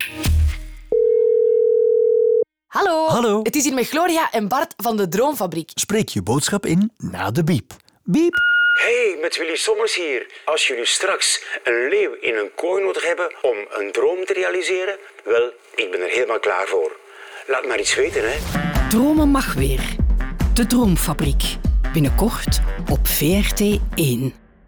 02.-Teaser-Radio-Willy-Sommers.wav